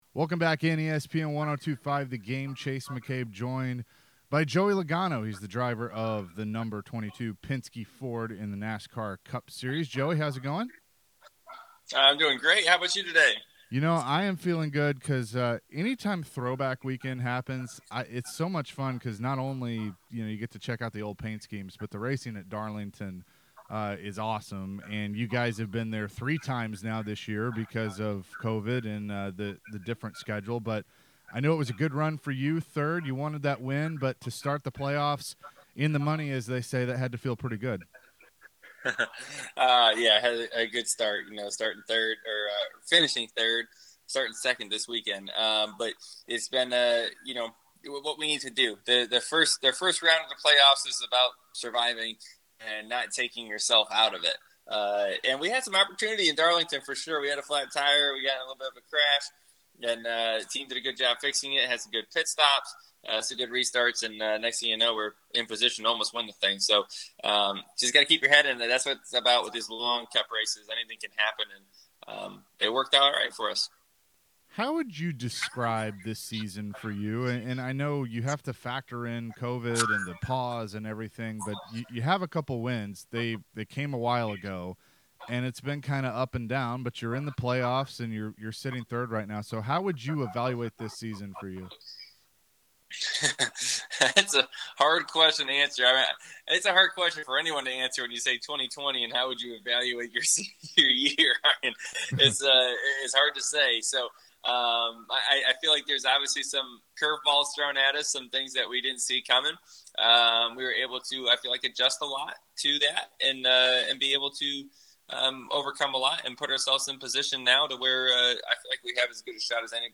Joey Logano Interview